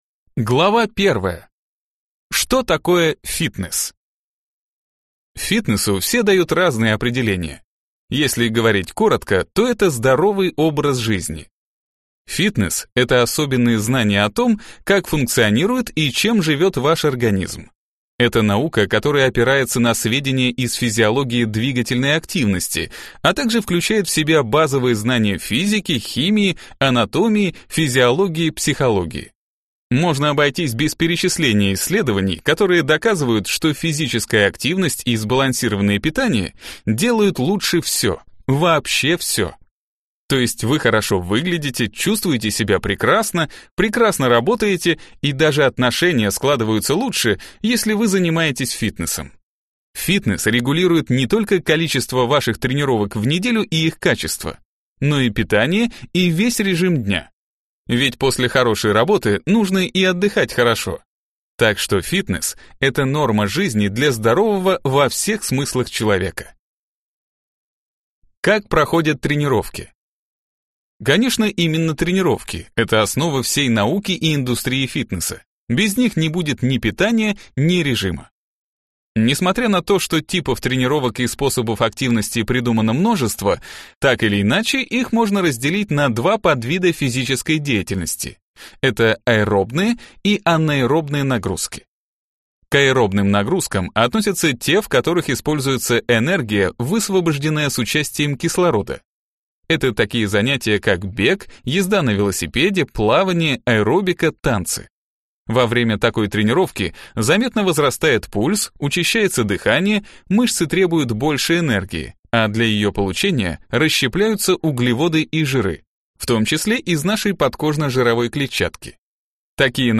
Аудиокнига Фитнес. Секретные методики спецслужб | Библиотека аудиокниг